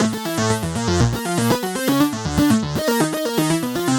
Index of /musicradar/french-house-chillout-samples/120bpm/Instruments
FHC_Arp C_120-A.wav